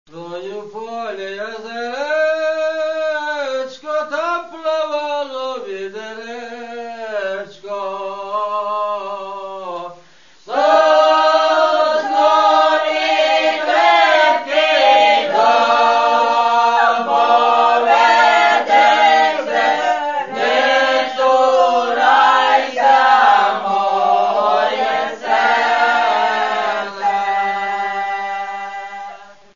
лірична